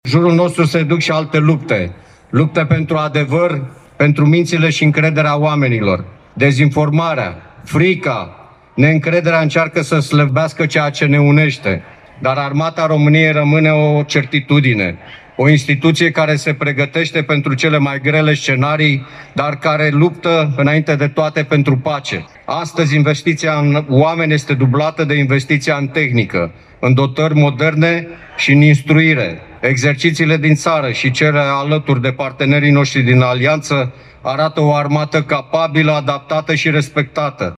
În discursul său, Generalul Gheorghiță Vlad, șeful Statului Major al Apărării, a spus că „trăim vremuri în care se vorbește mult despre război, dar mai puțin despre curaj”: